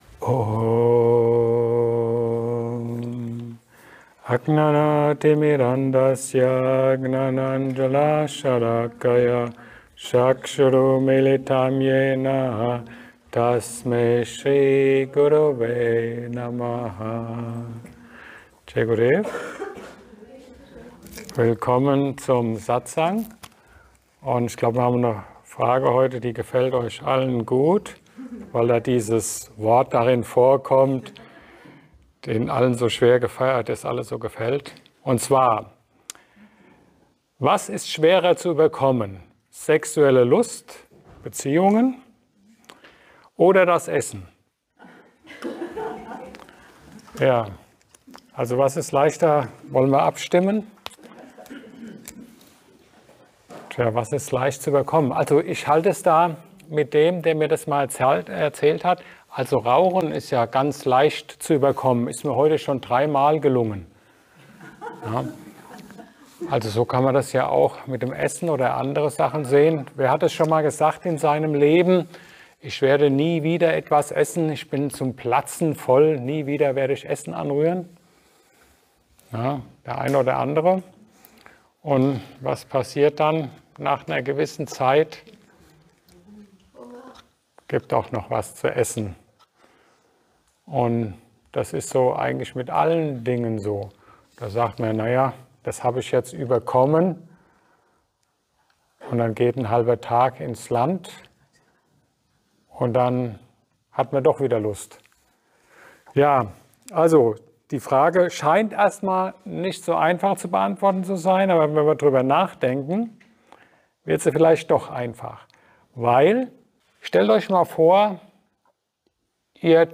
Ein Satsang